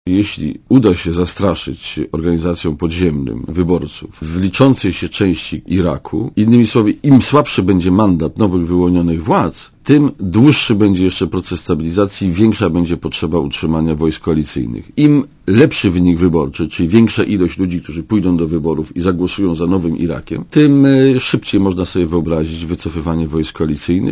(PAP) Z Iraku nie można uciekać, ale jeśli wybory wypadną porządnie, będzie można przyspieszyć wycofywanie naszych wojsk - to zdanie gościa Radia ZET - Bronisława Komorowskiego, posła Platformy Obywatelskiej.